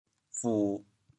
调: 低 国际音标 [pu]